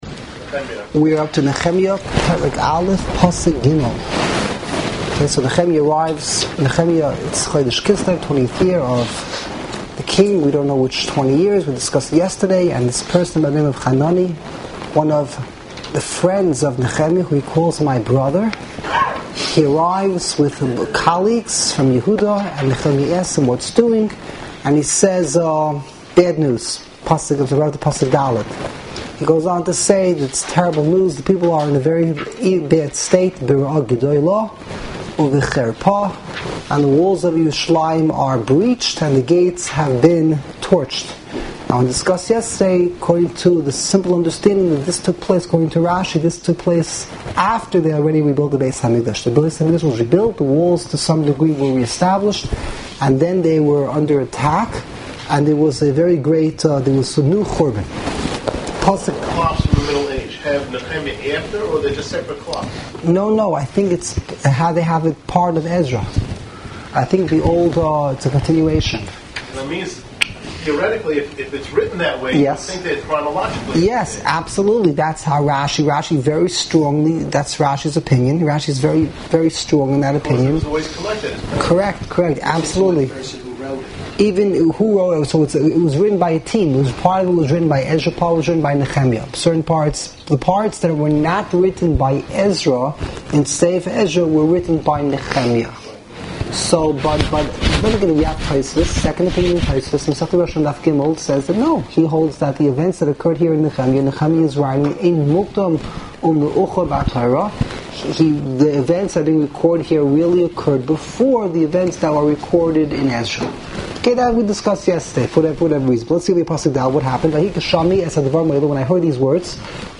Live Daily Shiurim